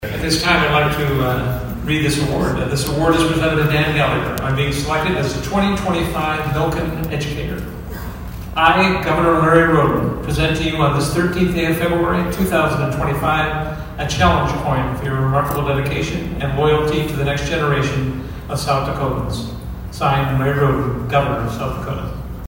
ABERDEEN, S.D.(HubCityRadio)- Students and faculty were in for a surprise of lifetime Thursday at Aberdeen Roncalli Middle & High School.
South Dakota Secretary of Education Joseph Graves reads the proclamation from Governor Rhoden.